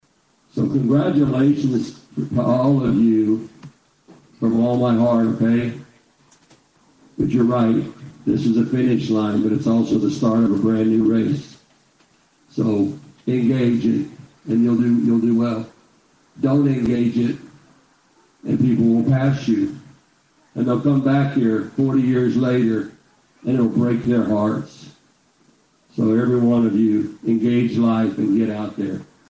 The Copan High School graduation was broadcast live on 104.9 KRIG and was sponsored by Bartnet IP and OK Federal Credit Union.